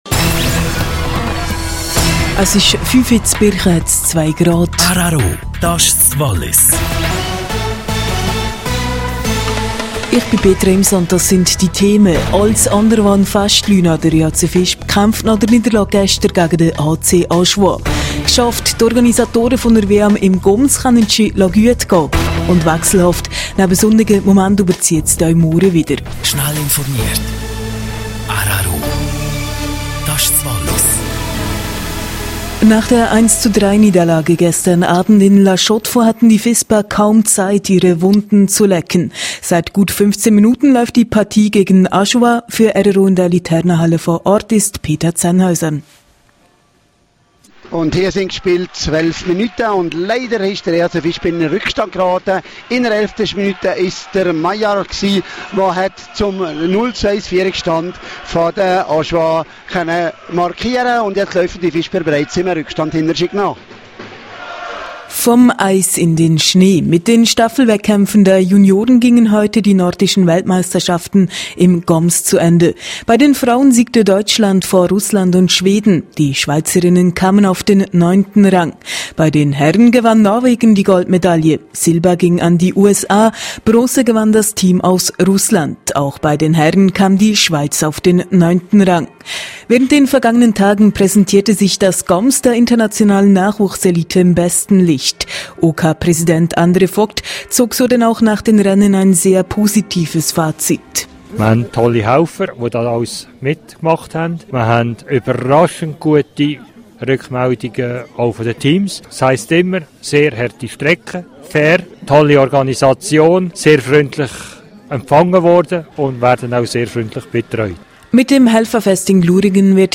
17:00 Uhr Nachrichten (3.91MB)